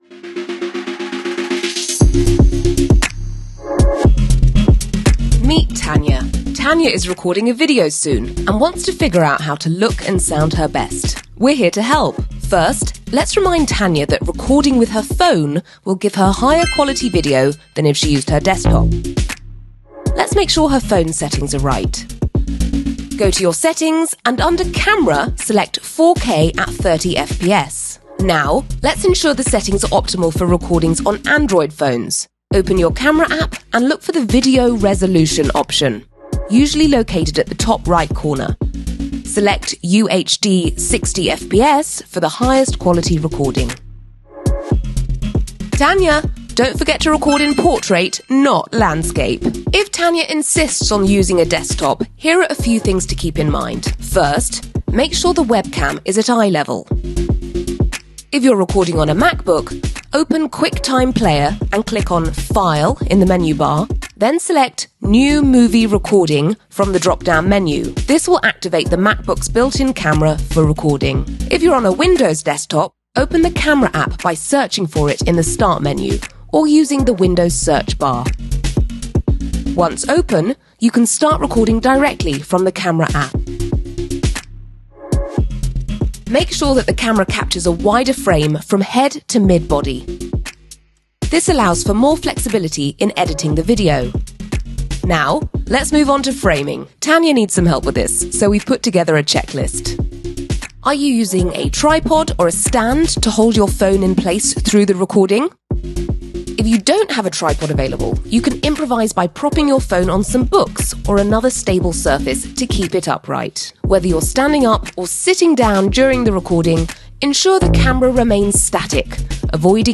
Voice Samples: Explainer Video
female